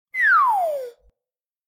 Whistle Slide Down Soundboard: Play Instant Sound Effect Button